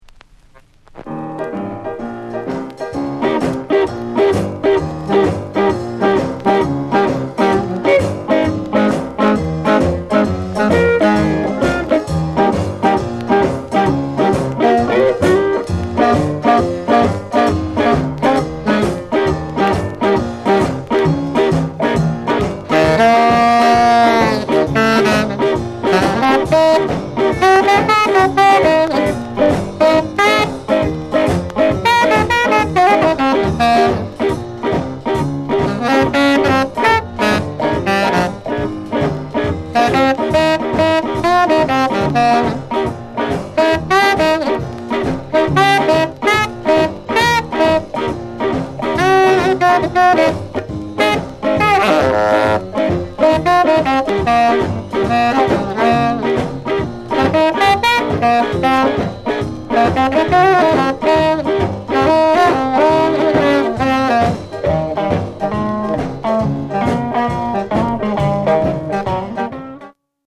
SHUFFLE INST